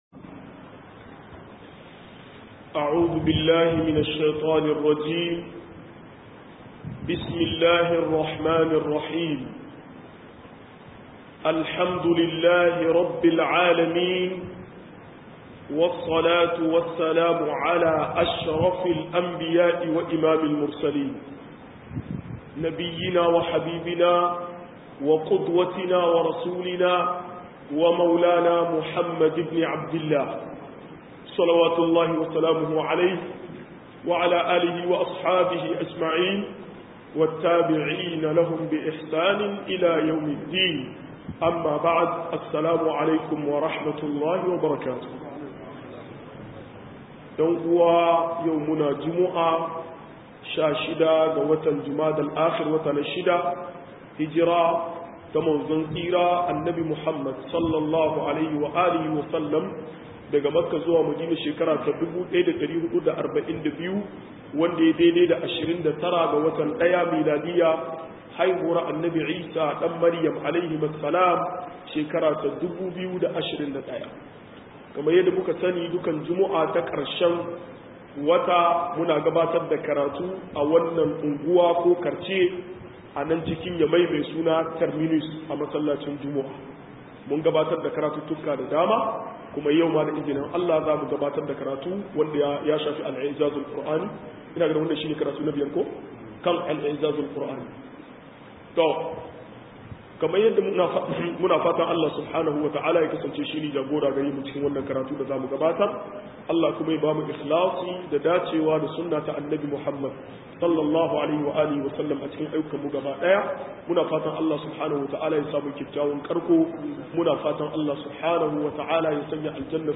113-Mu uzuzijin Alkuraani 5 - MUHADARA